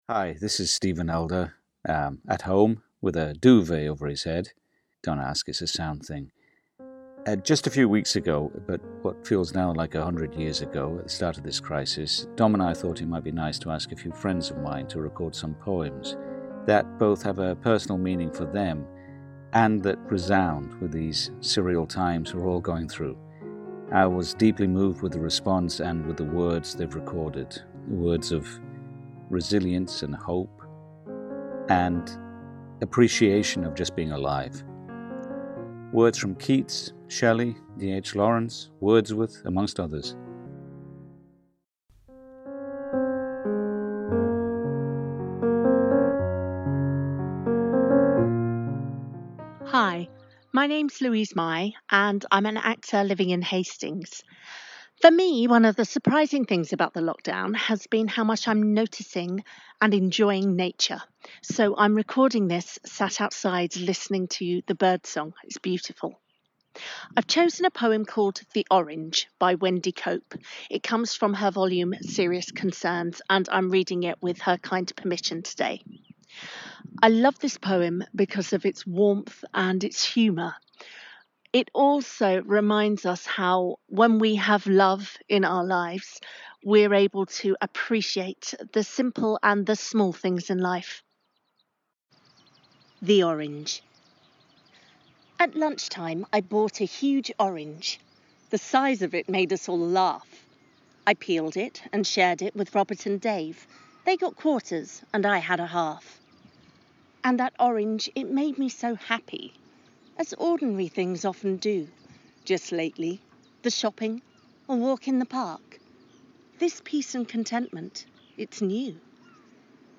Poetry for BBC Radio Kent
I decided to read one of my favourite poems The Orange, by Wendy Cope and to do it outside as I was aware that for many people unable to leave their homes, the sound of the birds would be as much of a comfort as the poem itself.